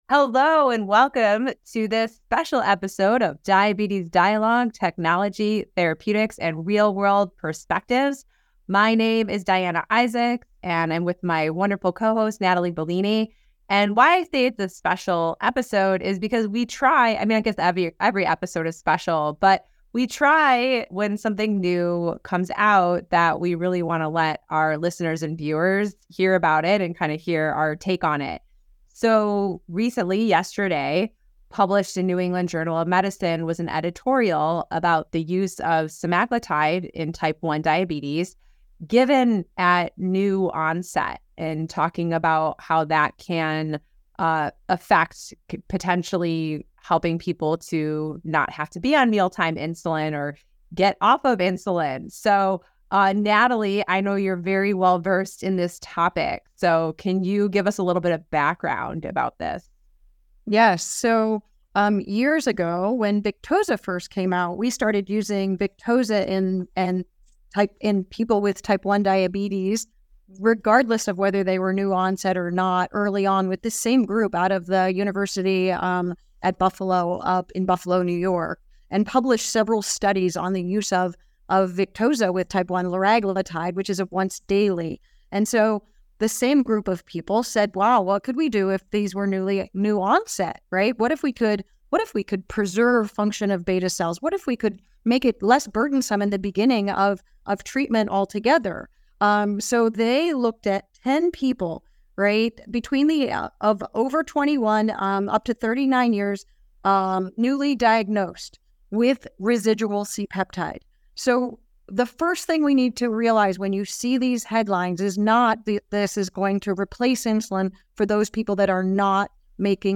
In this episode, hosts sit down for a discussion around a recent NEJM report assessing the effects of semaglutide use in people with type 1 diabetes, including the implications of the findings and their real-world experiences with GLP-1 use in people with type 1 diabetes.